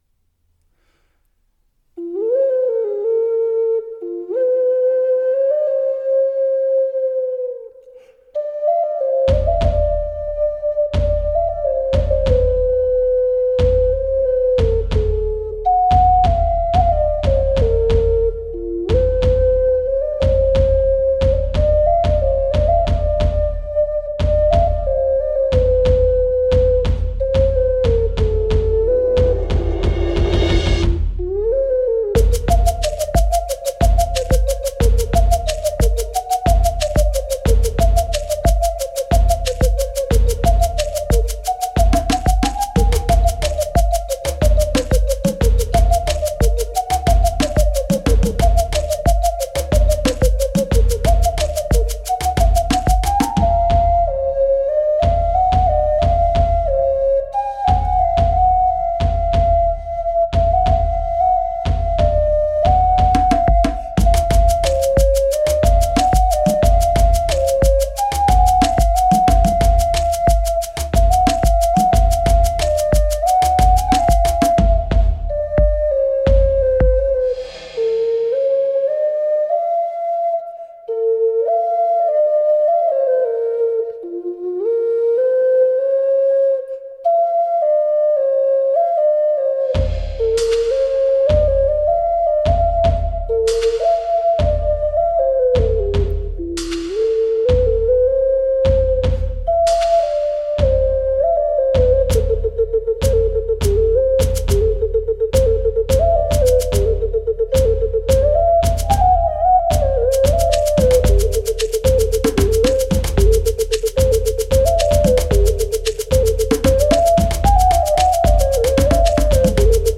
I've recorded an ocarina medley, but I've found that when played back on a cell phone (or a laptop with subpar speakers), certain notes have this buzz to them, like the speakers are vibrating and creating unwanted noise.
It creates a pure tone, with little color or timbre or distortion, etc. I know VERY little about recording, but I know that through headphones or a good sound system (like in my car), the mp3 sounds perfectly fine.
The percussion - specially the shaker on the right side was too 'up front' - knock the volume down a bit an add some reverb to instruments that need it. The low drum beating had good reverb, but not the hand drums or shakers
The low drum beats seem to have a lot of very low frequencies (under 50Hz).
Also, playing the original file through the phone speaker, the drum and shakers are WAY too loud for the ocarina.